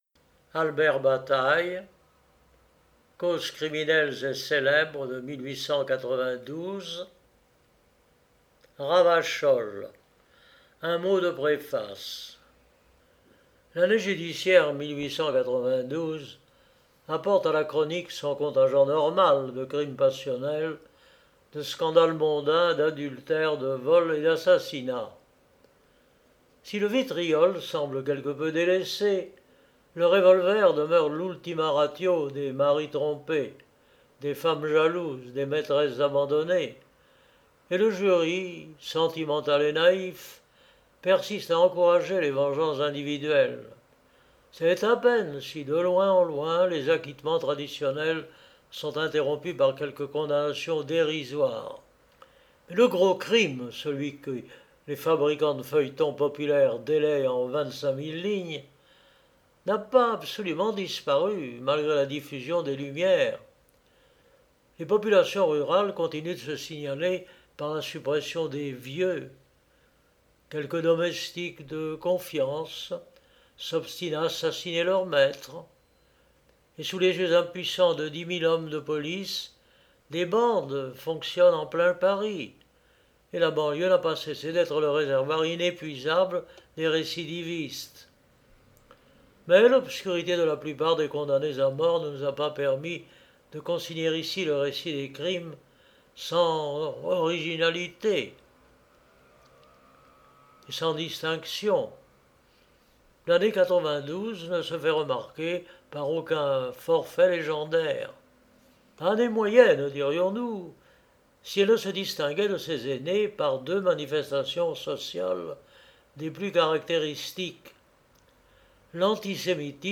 Genre : Histoire « L’année 1892 ne se fait remarquer par aucun forfait légendaire.